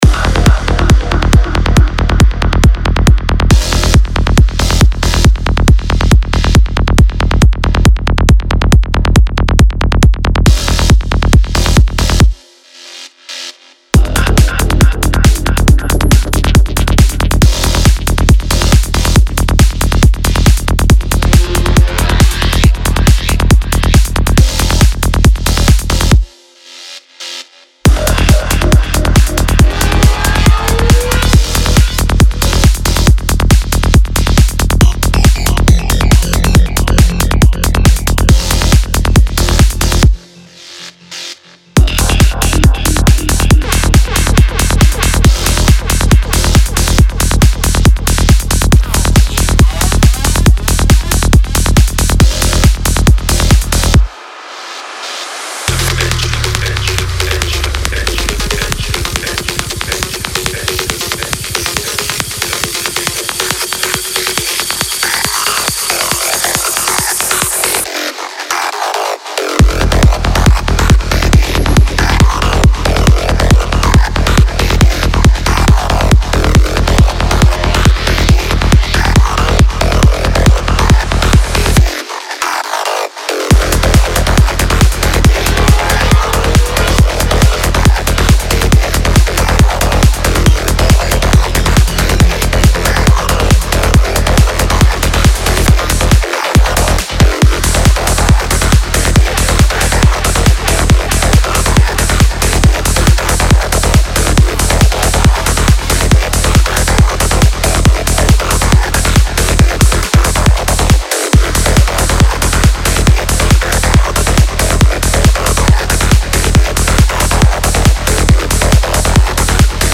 潜入迷幻音效设计的新维度！